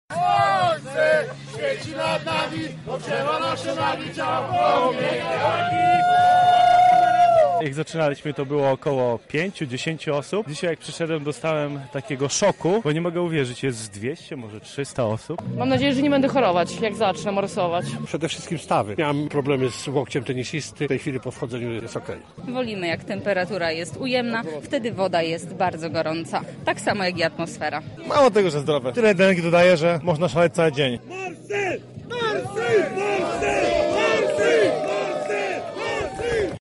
Na miejscu z Morsami rozmawiała nasza reporterka: